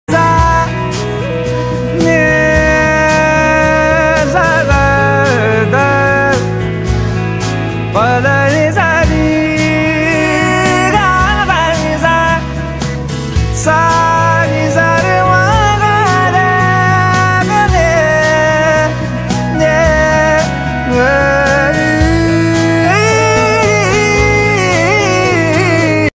This melodious sargam tune
soothing musical vibe